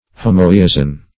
Homoiousian \Ho`moi*ou"si*an\, a.